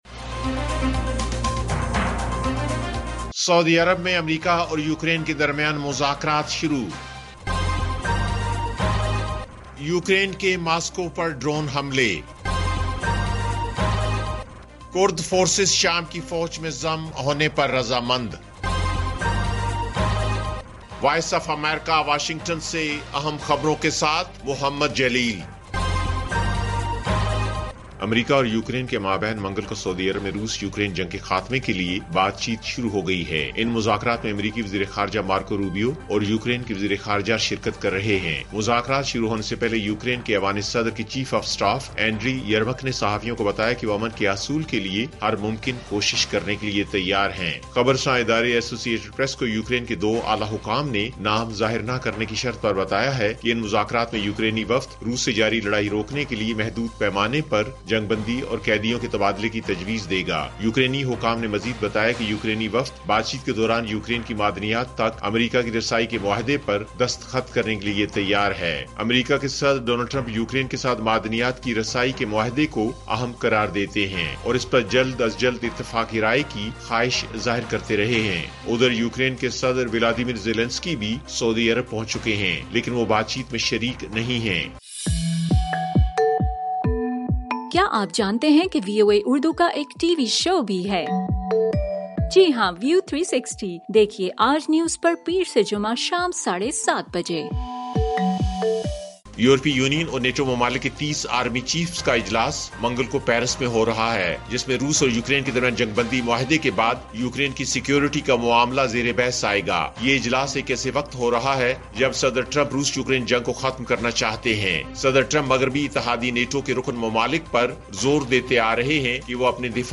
ایف ایم ریڈیو نیوز بلیٹن: شام 7 بجے